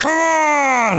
Line of King K. Rool in Donkey Kong 64.